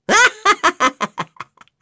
Neraxis-Vox_Happy3.wav